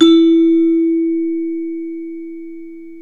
CELESTE E2.wav